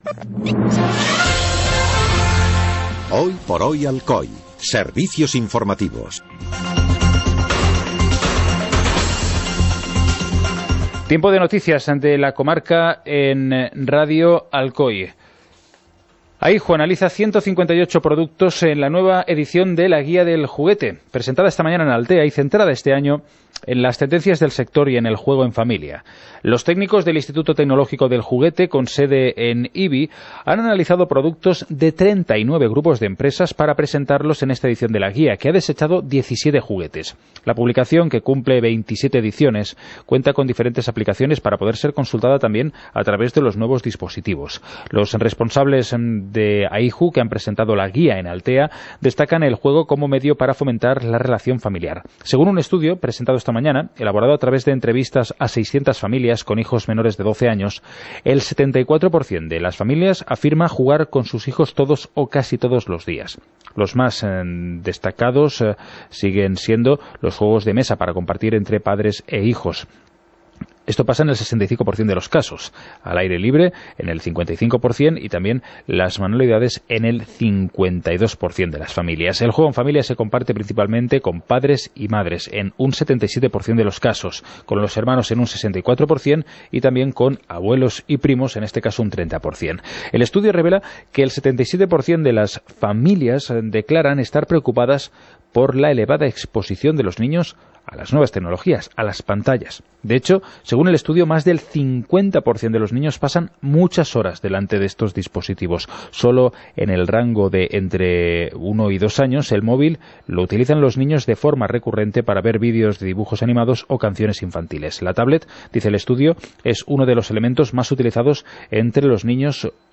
Informativo comarcal - miércoles, 08 de noviembre de 2017